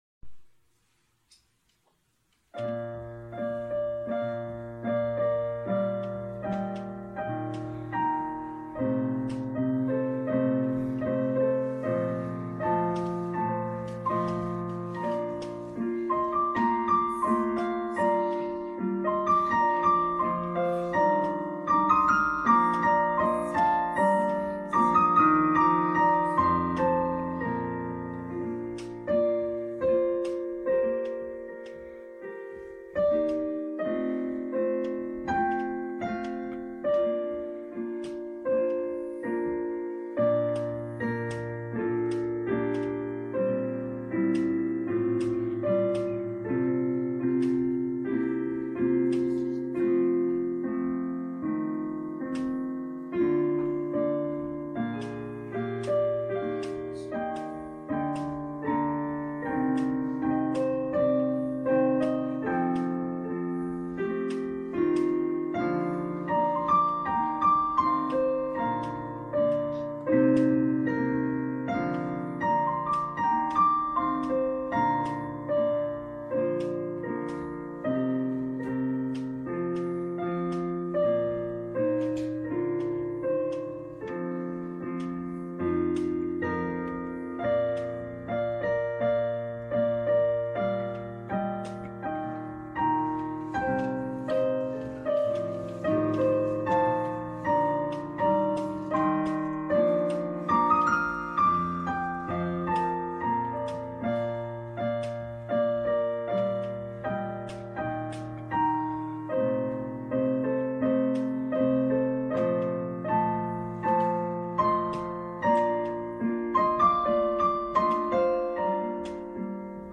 Klaviermusik